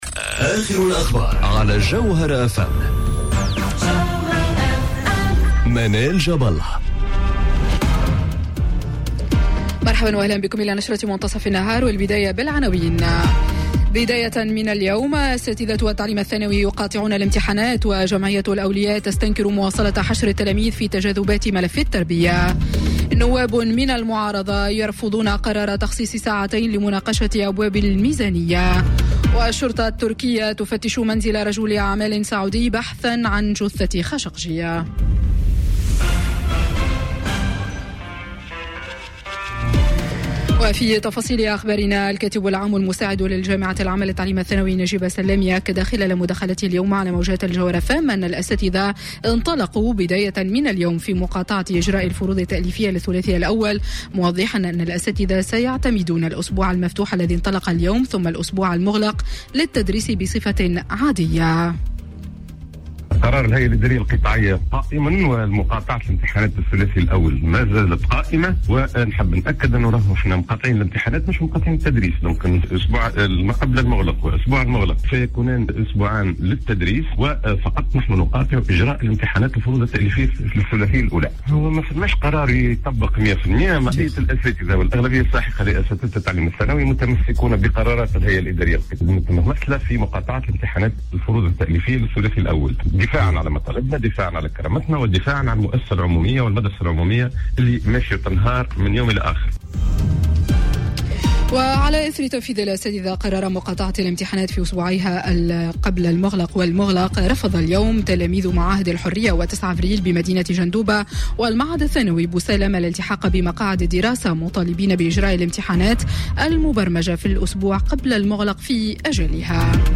نشرة أخبار منتصف النهار ليوم الإثنين 26 نوفمبر 2018